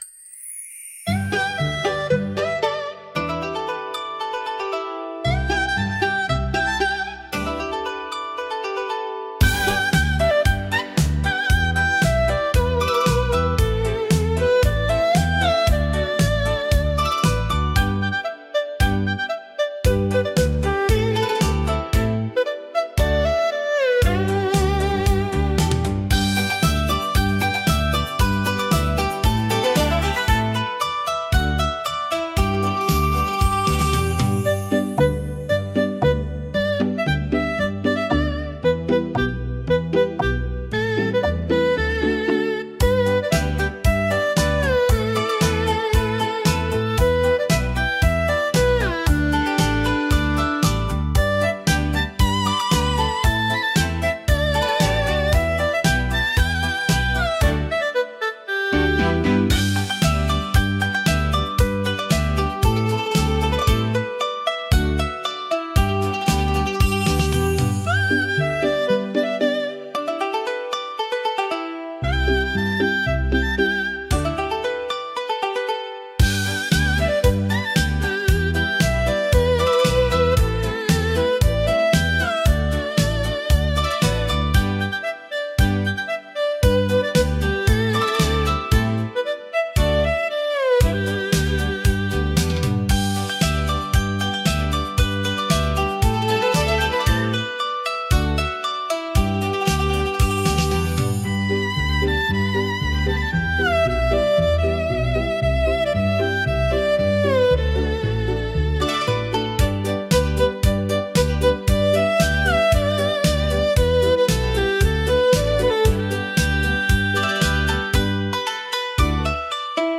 聴く人の心に深い感動と癒しを届ける優雅で情緒豊かなジャンルです。